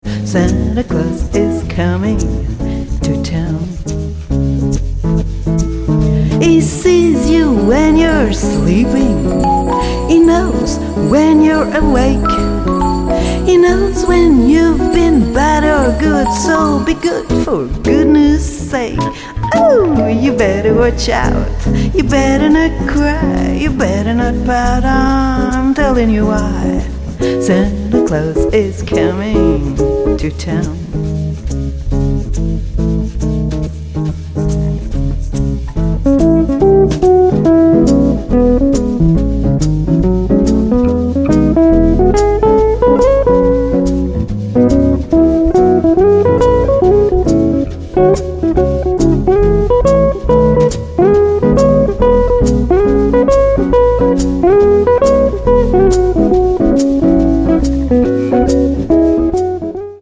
guitare cordes de nylon et percussions
contrebasse
guitare électrique